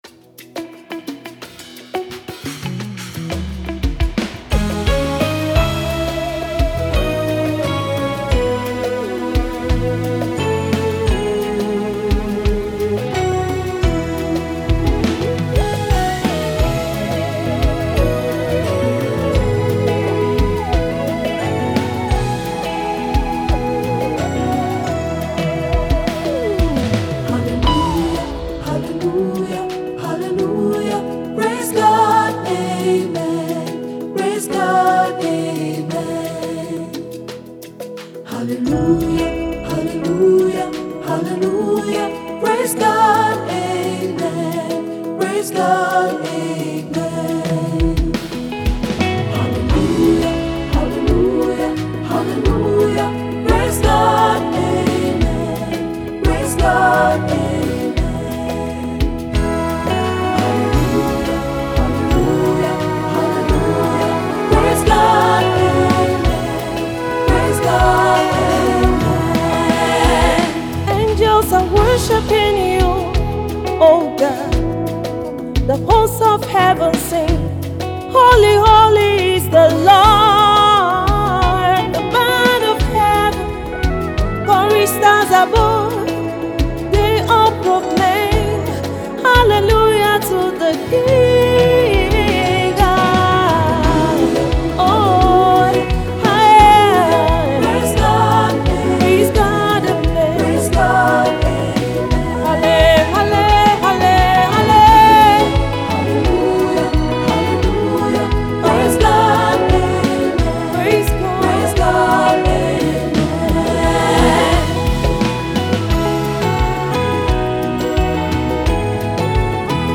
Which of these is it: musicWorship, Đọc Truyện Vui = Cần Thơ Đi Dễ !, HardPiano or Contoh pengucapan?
musicWorship